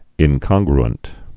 (ĭn-kŏnggr-ənt, ĭnkŏn-grənt)